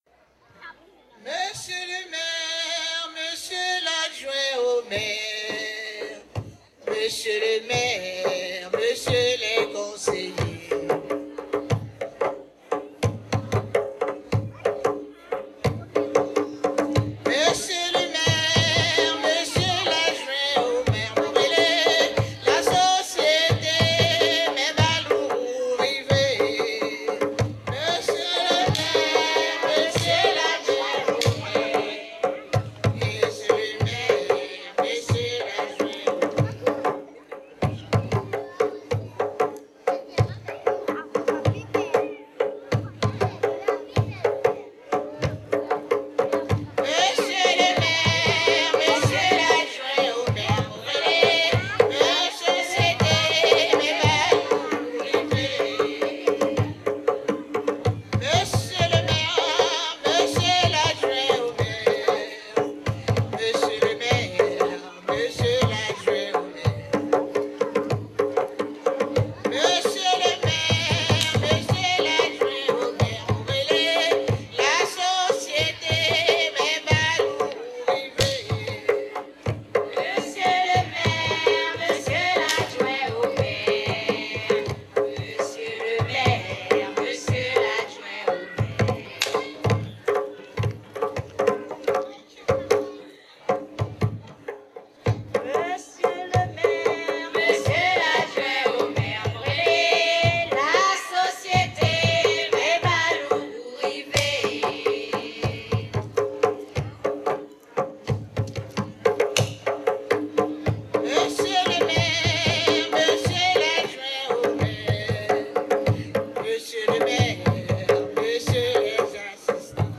Soirée Mémorial
Saint-Georges-de-l'Oyapoc
Pièce musicale inédite